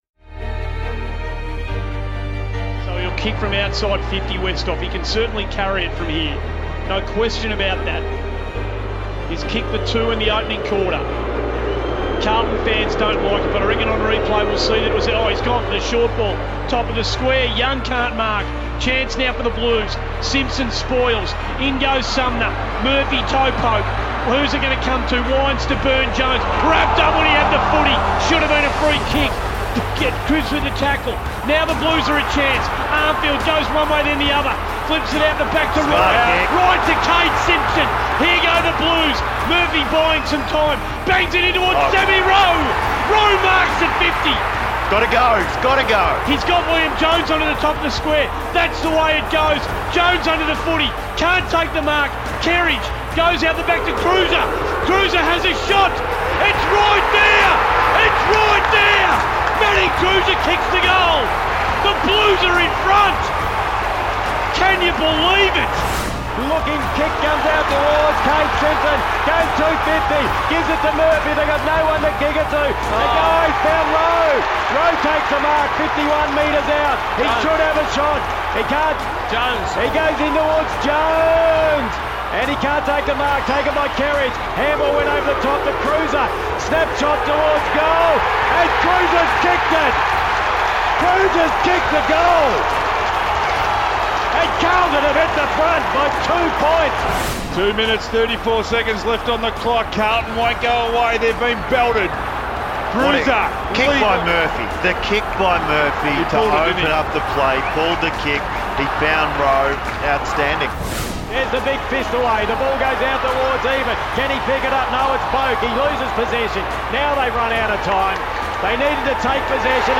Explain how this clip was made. Replay SEN 1116 and FIVEaa's call of the final moments of Carlton's thrilling victory over Port Adelaide.